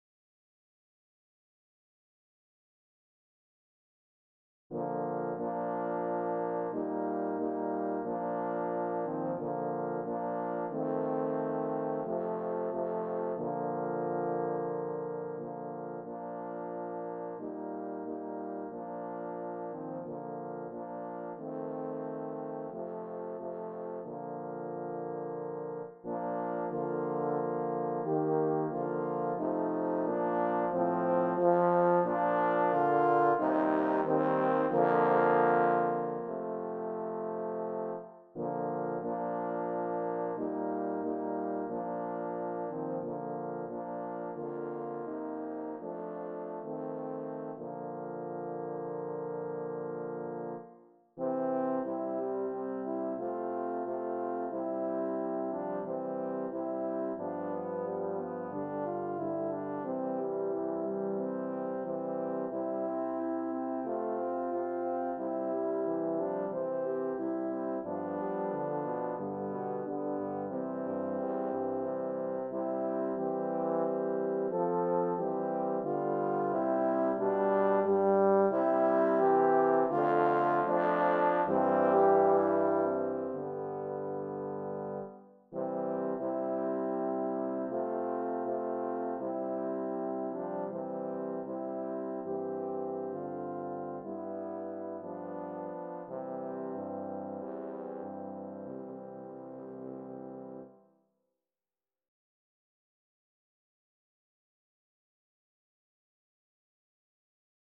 Hymn Four Horns number 19
This is MY JESUS, I LOVE THEE by Gordan. I used the FINALE composition program to create the horn quartet.